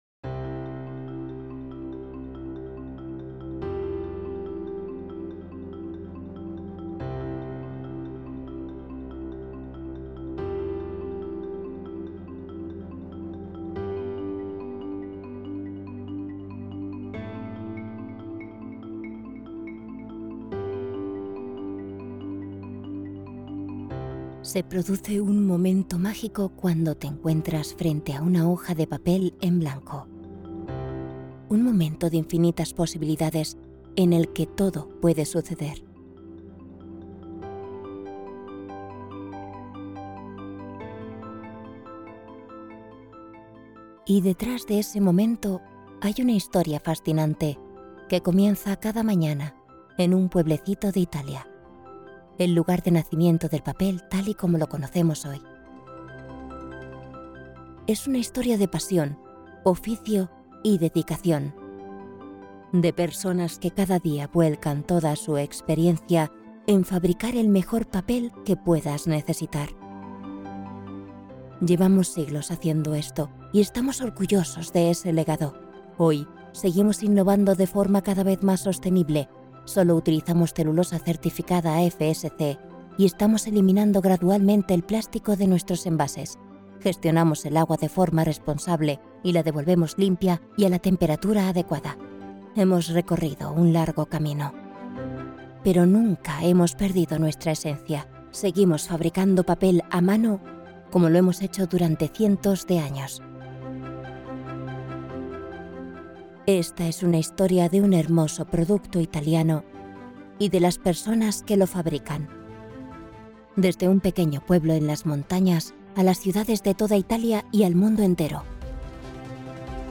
Commercial, Young, Natural, Versatile, Soft
Corporate